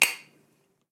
Apertura de un envase metálico 1
Cocina - Zona de preelaboración
Sonidos: Acciones humanas
Sonidos: Hogar